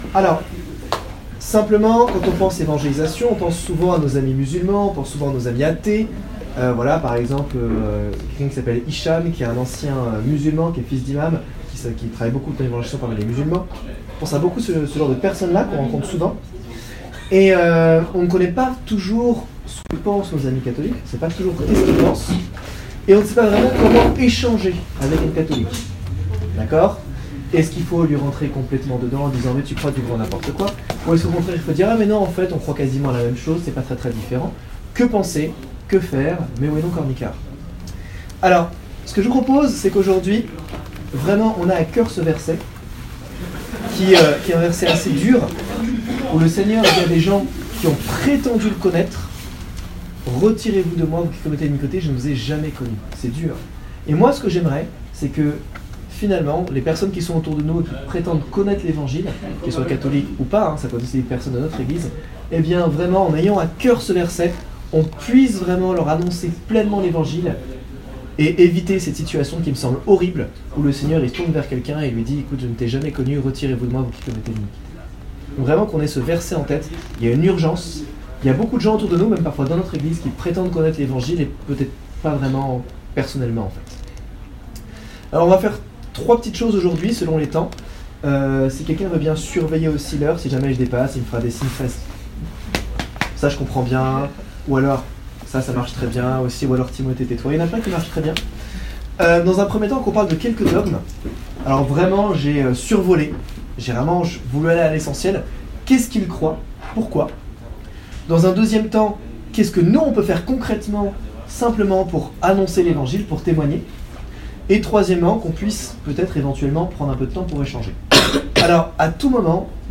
Rencontre CFC #6 du 2 février 2019 à l’église de Massena
4-Atelier-evangelisation-parmi-les-catholiques.mp3